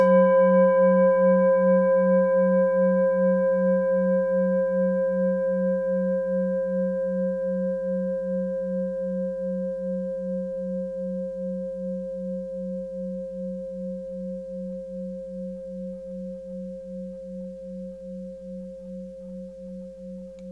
Klangschale Orissa Nr.1
Klangschale-Gewicht: 700g
Klangschale-Durchmesser: 16,9cm
Sie ist neu und wurde gezielt nach altem 7-Metalle-Rezept in Handarbeit gezogen und gehämmert.
Die Frequenz des Jupiters liegt bei 183,58 Hz und dessen tieferen und höheren Oktaven. In unserer Tonleiter ist das in der Nähe vom "Fis".
klangschale-orissa-1.wav